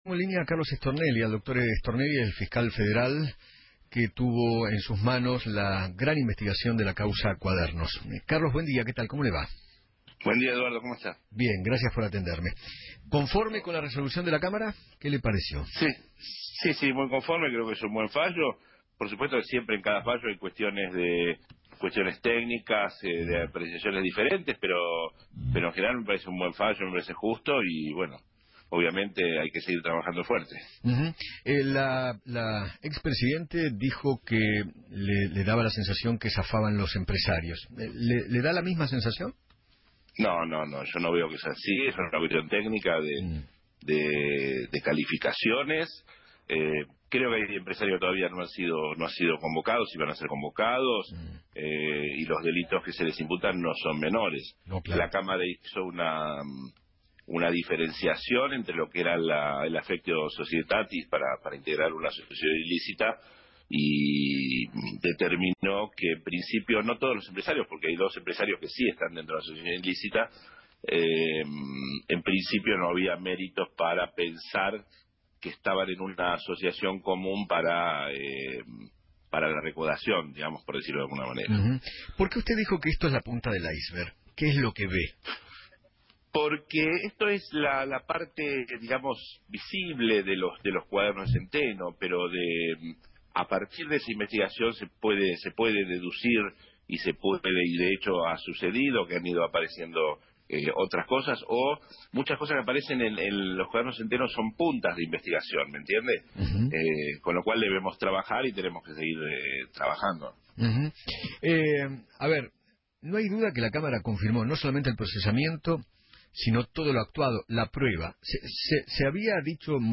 El fiscal Carlos Stornelli habló en Feinmann 910 y dijo que “Estoy conforme con la resolución de la Cámara, me parece un fallo justo y hay que seguir trabajando fuerte. Yo no veo que zafen los empresarios, hay empresarios que serán convocados y los delitos que se le imputan no son menores. Lo que se hizo fue una identificación, hay dos empresarios que están dentro de la asociación ilícita”